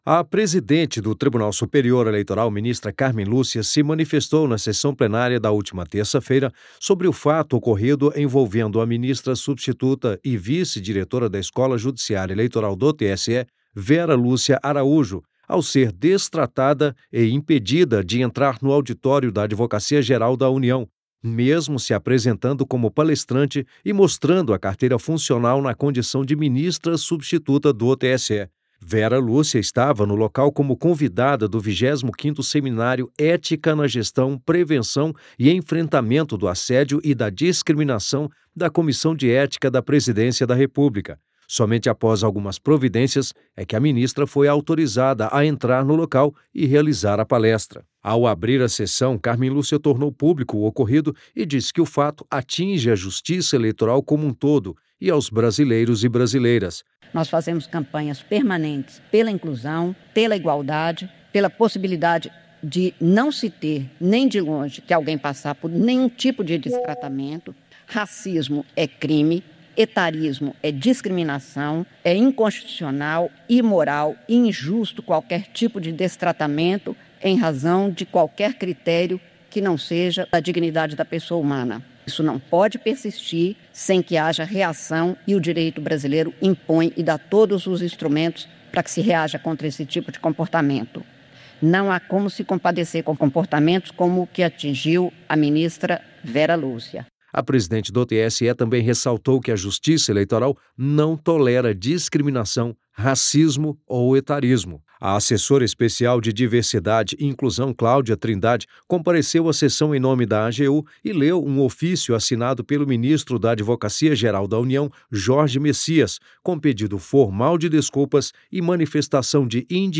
Ao abrir a sessão desta terça (20), a ministra Cármen Lúcia repudiou qualquer tipo de tratamento que viole a dignidade da pessoa humana.